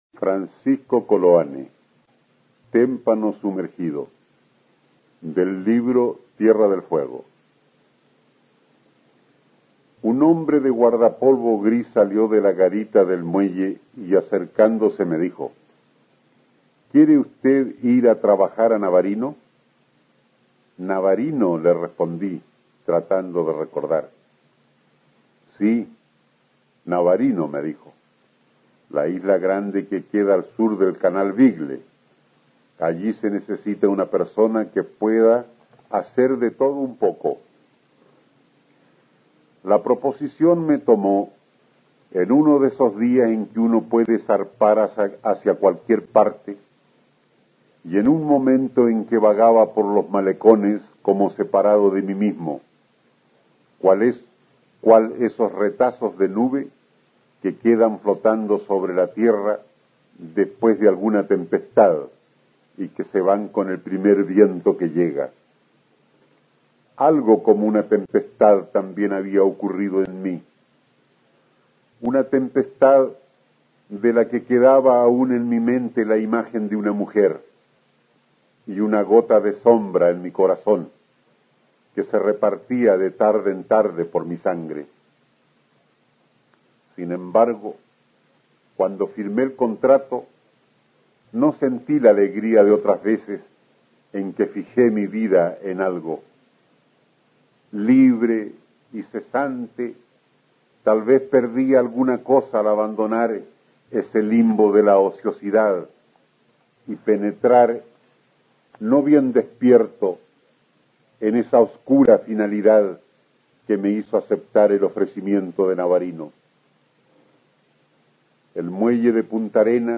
Aquí podrás escuchar al escritor chileno Francisco Coloane (1910-2002) leyendo su cuento Témpano sumergido, incluido en el libro "Tierra del Fuego" (1956). El autor, que fue galardonado en 1964 con el Premio Nacional de Literatura, nos sumerge en este relato en la cruda atmósfera de las abandonadas islas del canal Beagle.